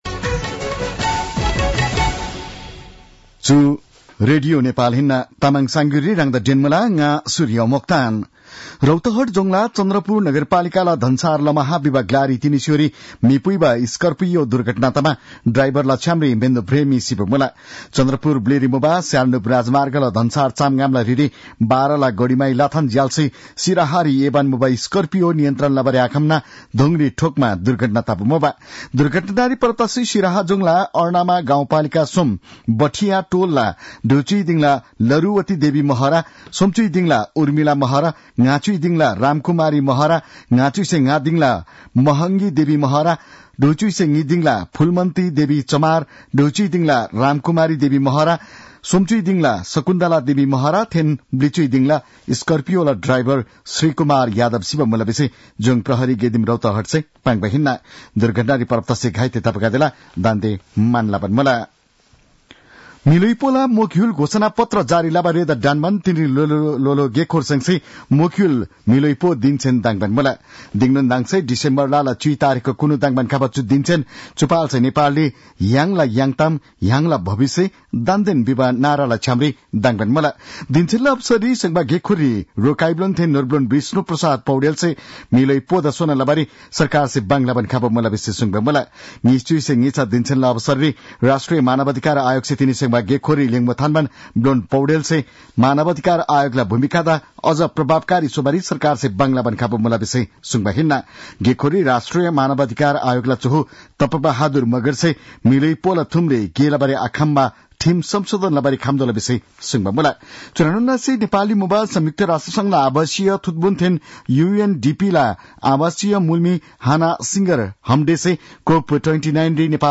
तामाङ भाषाको समाचार : २६ मंसिर , २०८१